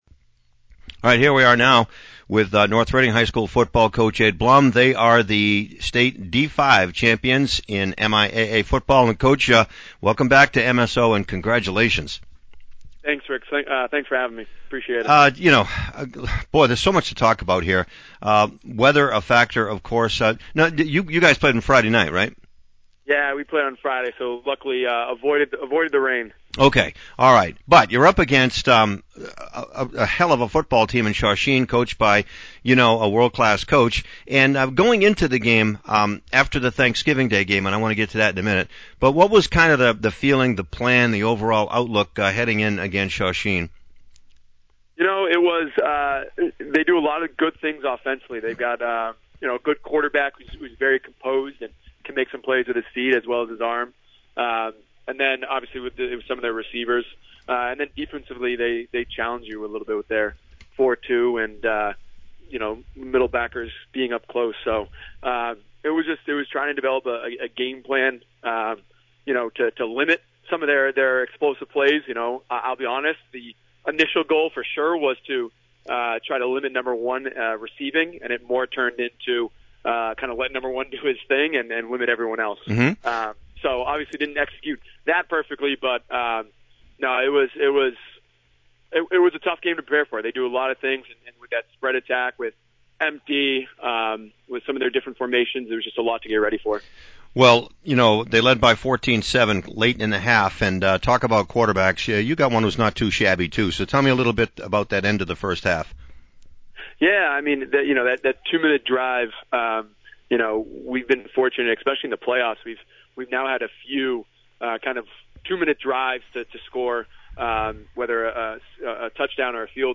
(Audio) Final Post-game for the Season